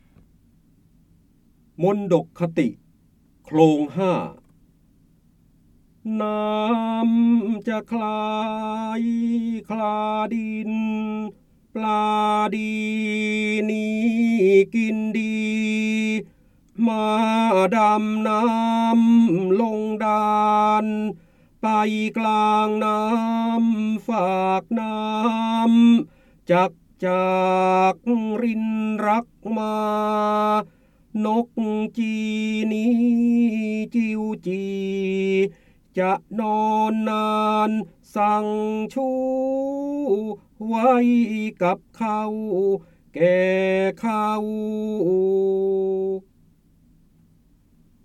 เสียงบรรยายจากหนังสือ จินดามณี (พระโหราธิบดี) มณฑกคติ โคลงห้า
คำสำคัญ : จินดามณี, ร้อยกรอง, การอ่านออกเสียง, พระเจ้าบรมโกศ, พระโหราธิบดี, ร้อยแก้ว
ลักษณะของสื่อ :   คลิปเสียง, คลิปการเรียนรู้